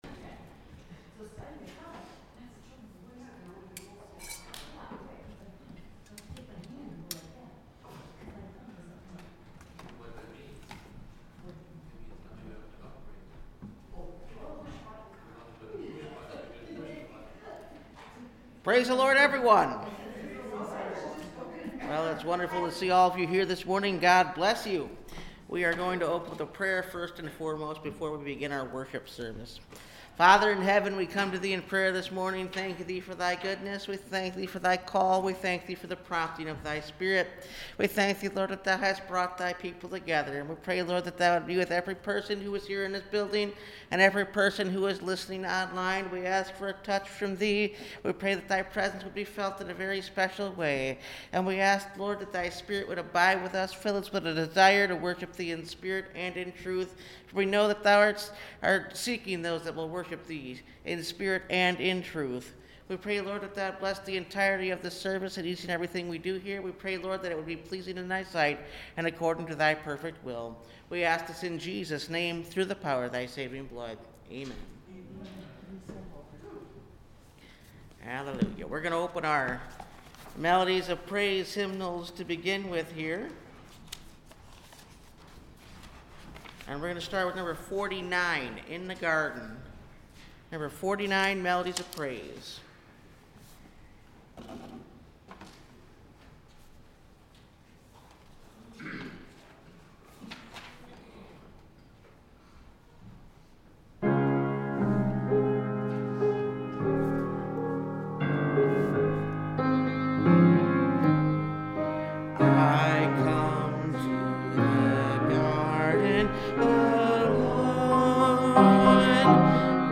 Children Of The Highest – Last Trumpet Ministries – Truth Tabernacle – Sermon Library
Service Type: Sunday Morning